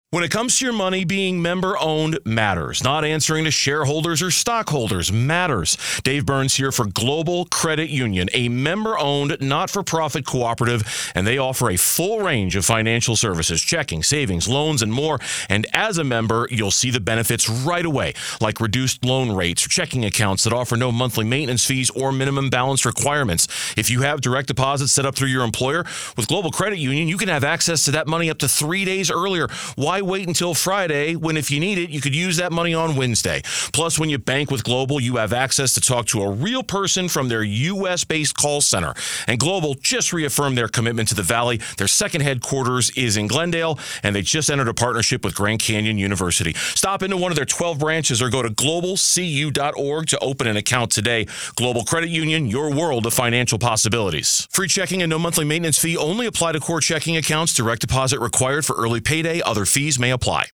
Radio Samples